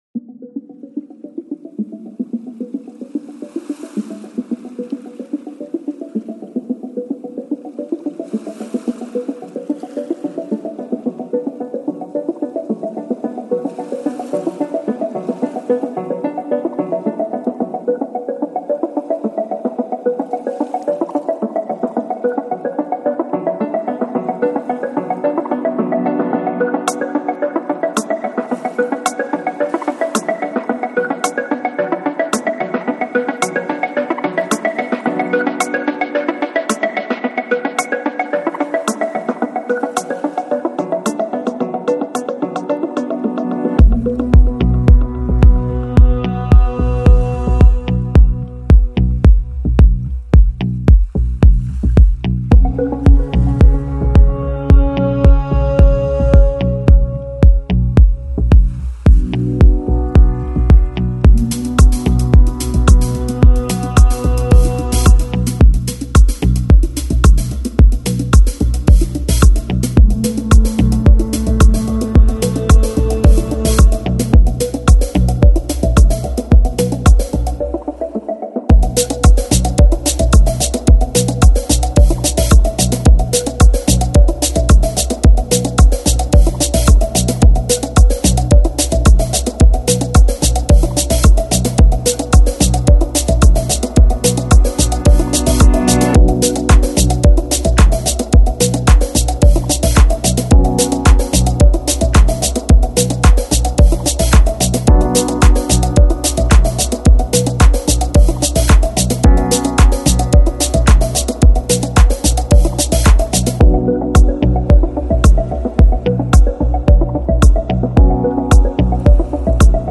Жанр: Progressive House, Deep House, Downtempo, Afro House